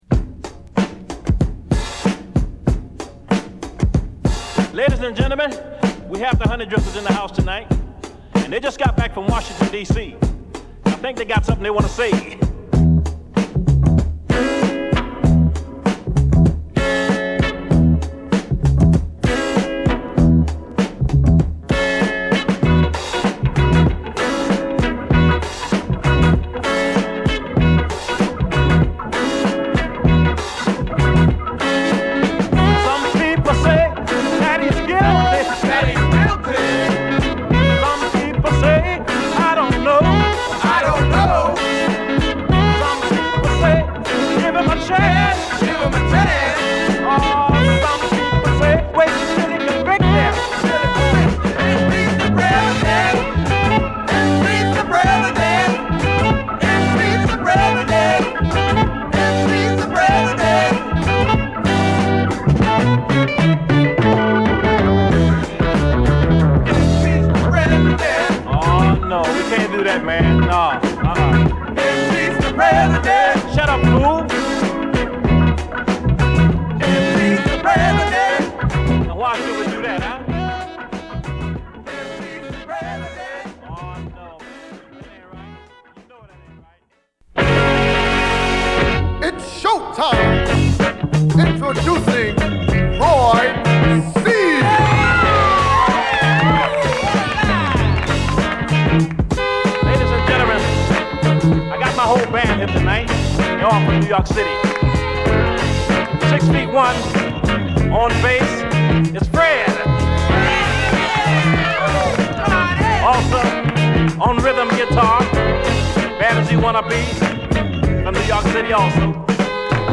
こちらもブレイクこそ無いが、同路線のミディアム・ソウル／ファンクチューンで◎。